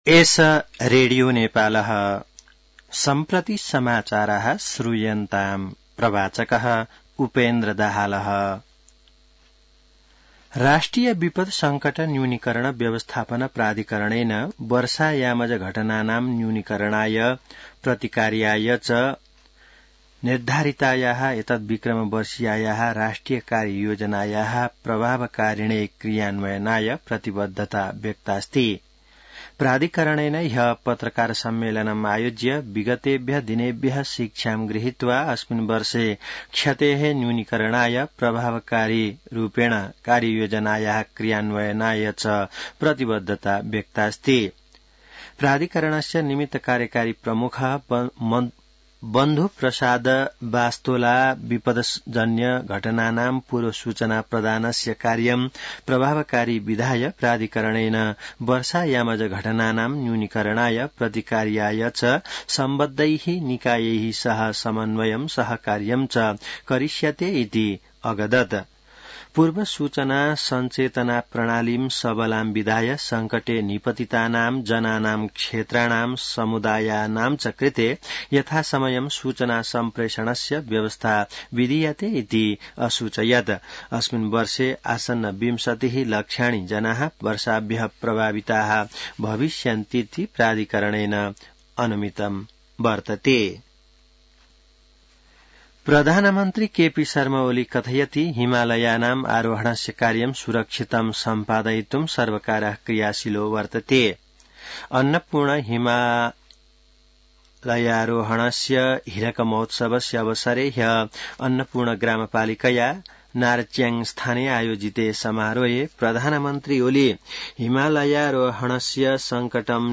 संस्कृत समाचार : २१ जेठ , २०८२